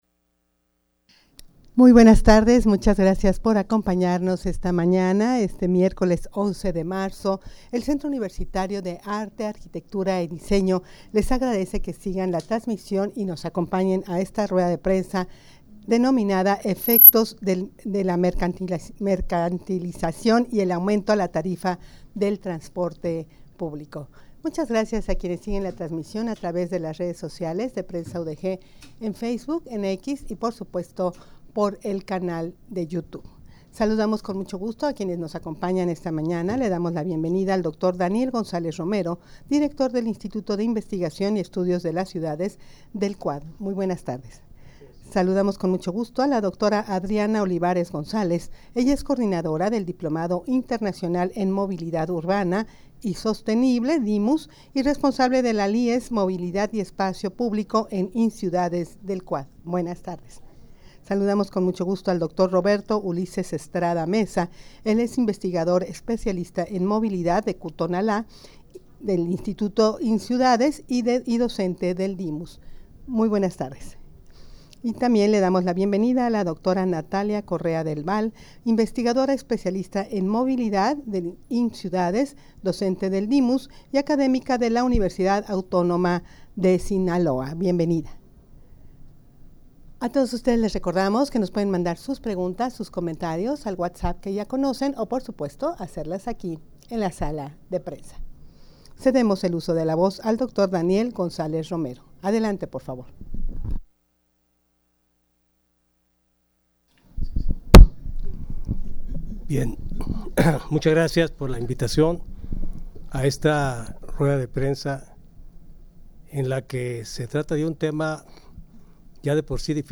Audio de la Rueda de Prensa
rueda-de-prensa-efectos-del-aumento-a-la-tarifa-del-transporte-publico.mp3